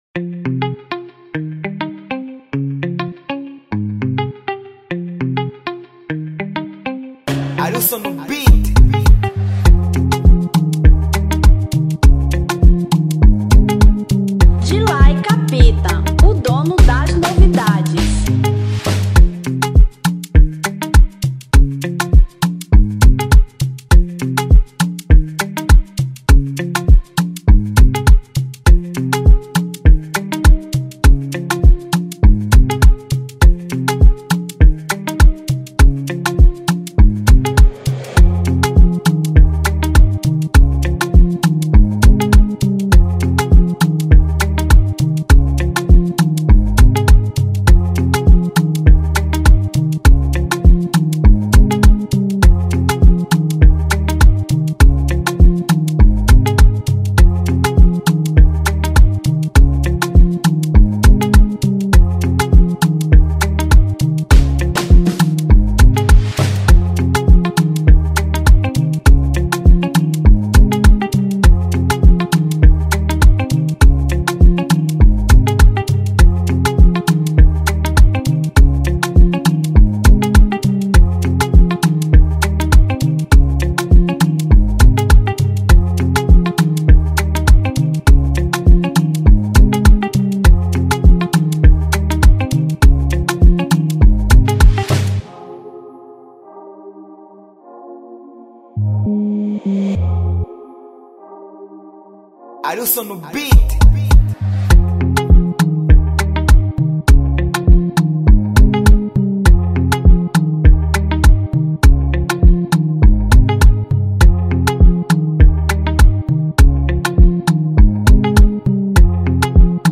Instrumental 2025